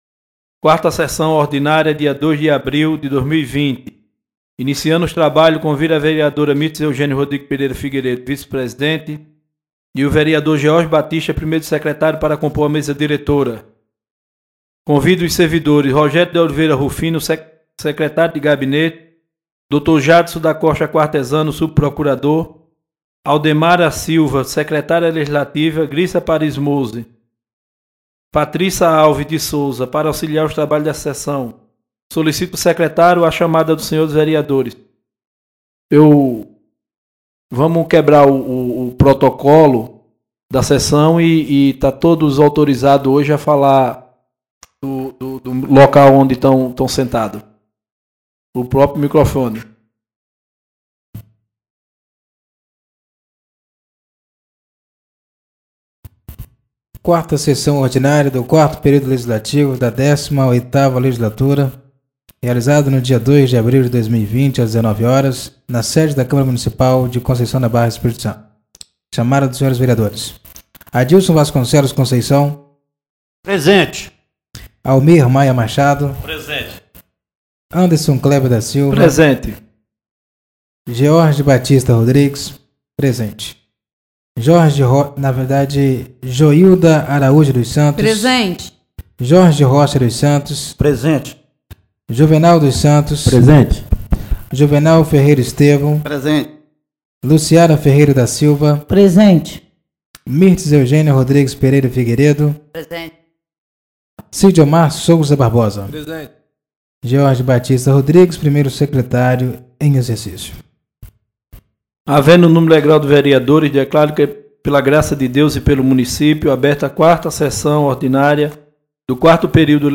4ª Sessão Ordinária do dia 02 de abril de 2020